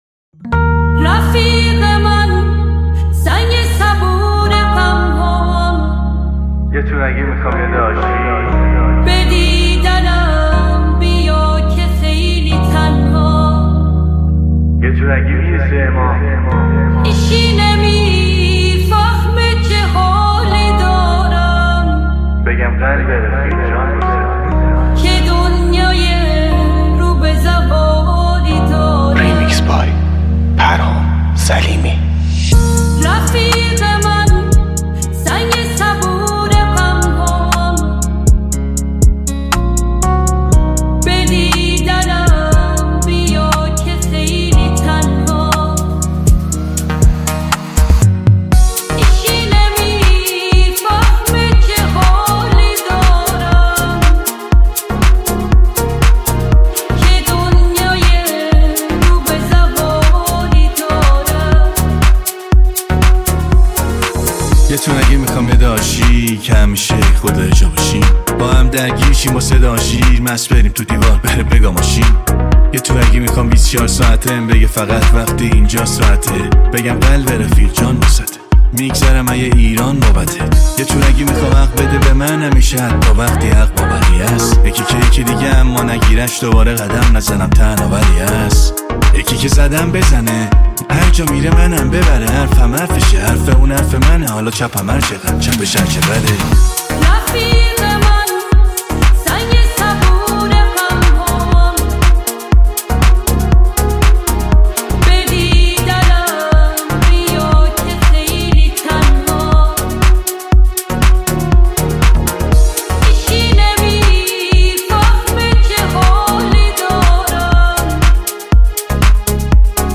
ژانر: پاپ & رپ